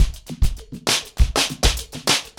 PrintOuts-100BPM.13.wav